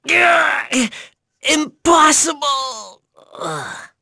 Neraxis-Vox_Dead.wav